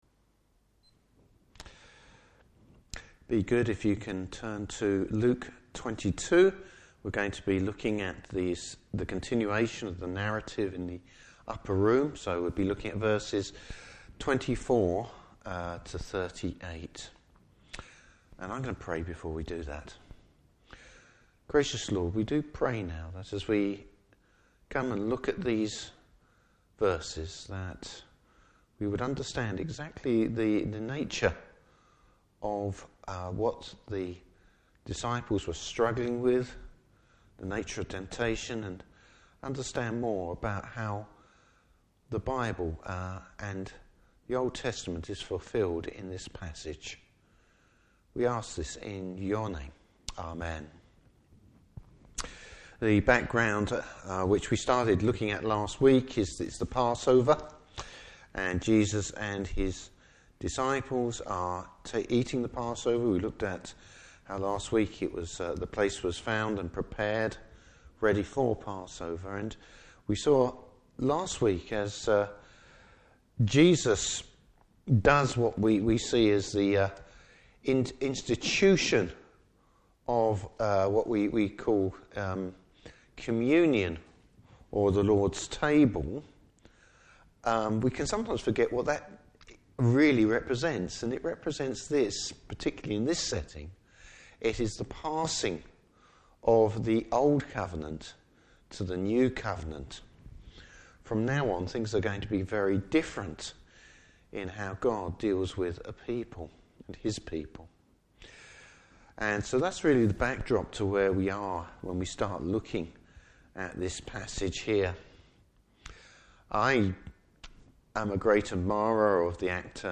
Service Type: Morning Service The disciples failure to understand true discipleship and Jesus purpose.